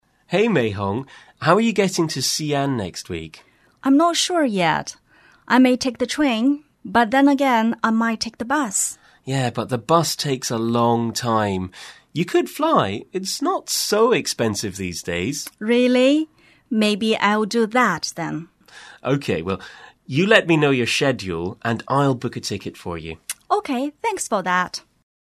英语初学者口语对话第03集：你下周怎么去西安？